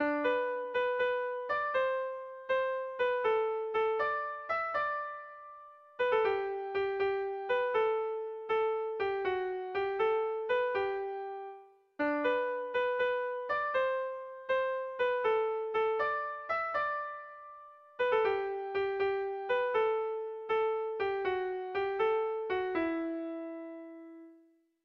Bertso melodies - View details   To know more about this section
Sentimenduzkoa
Zortziko txikia (hg) / Lau puntuko txikia (ip)
A-B-A-B